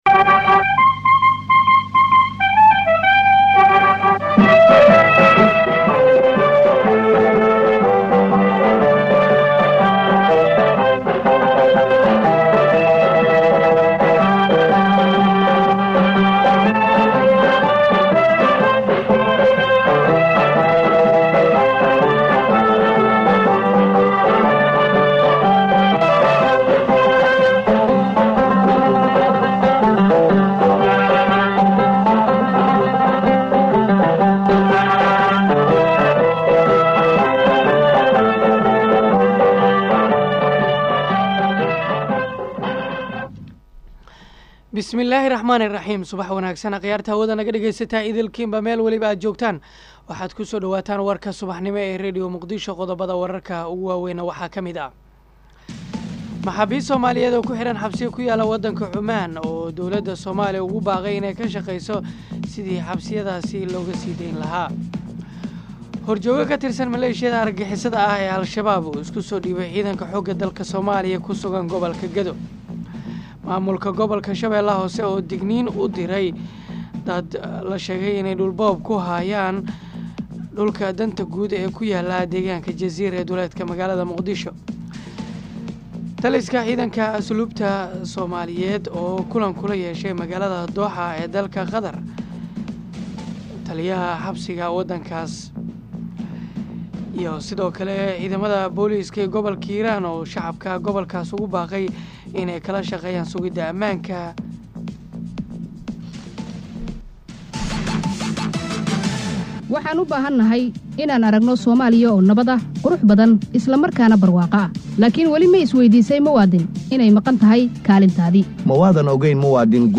Dhageyso: Warka Subax ee Radio Muqdisho